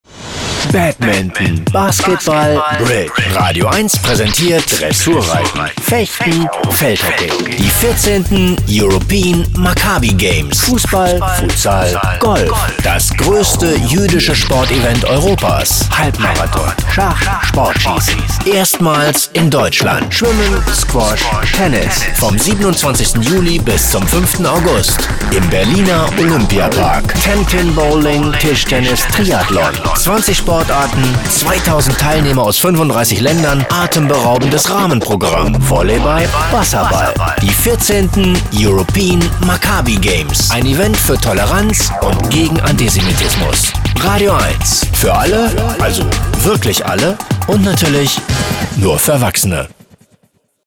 EMG2015 Radio Spot im rbb
radioeins-Trailer-EMG-2015.wav